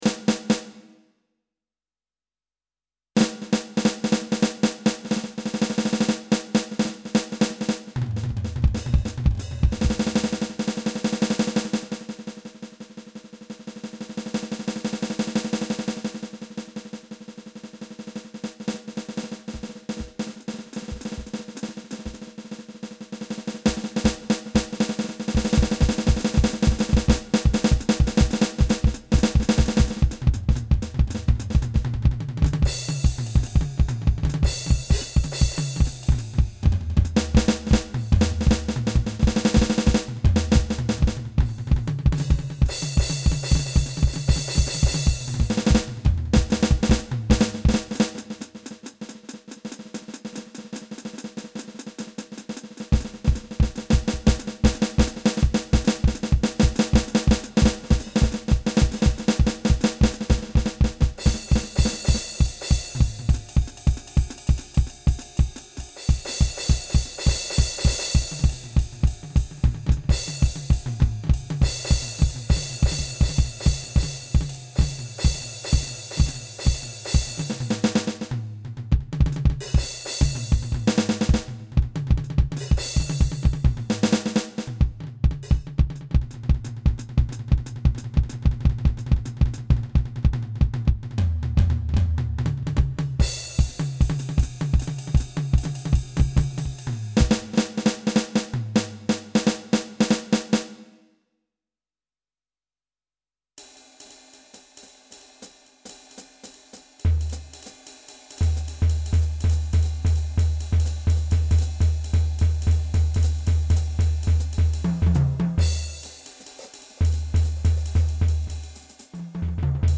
Essais percussifs